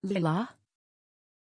Pronunciation of Lylah
pronunciation-lylah-tr.mp3